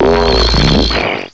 cry_not_stunfisk.aif